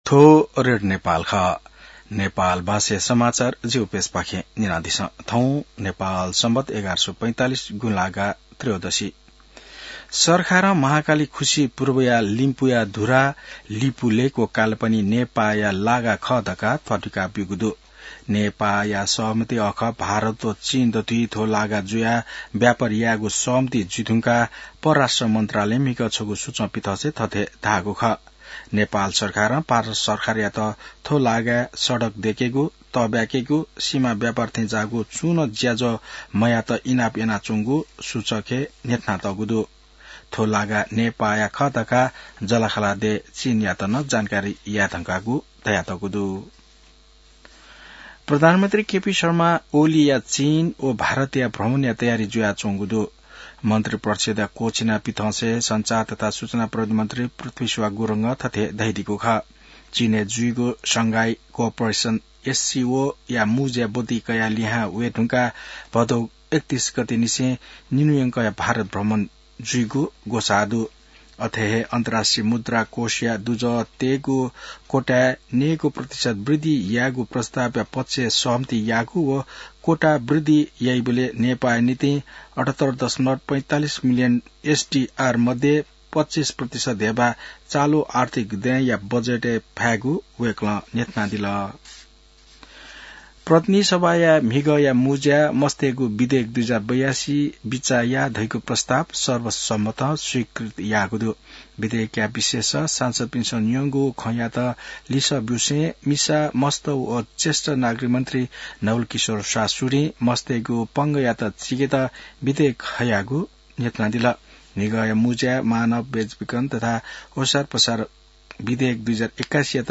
नेपाल भाषामा समाचार : ५ भदौ , २०८२